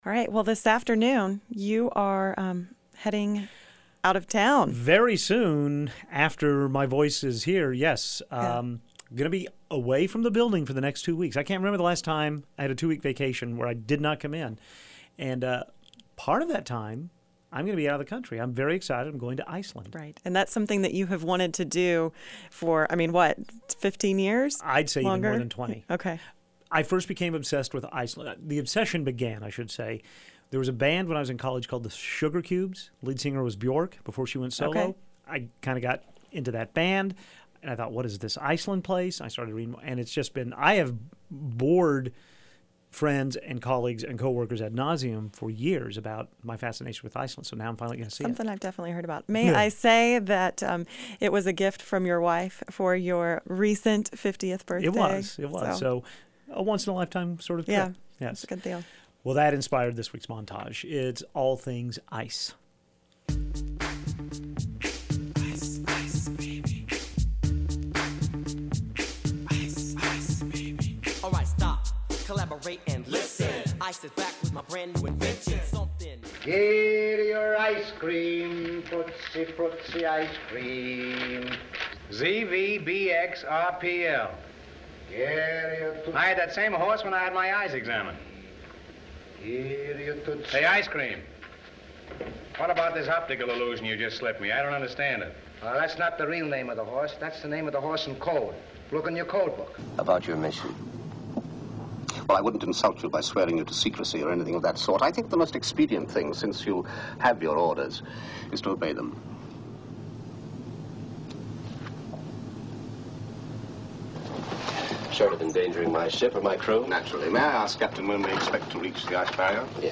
Here is a listing of the ten clips included in this week’s montage of ice-related items. Vanilla Ice sings his biggest (only?) hit, "Ice, Ice, Baby."
Camaro-ready rock and roll from Foreigner.
A portion of the trailer to the first Ice Age film.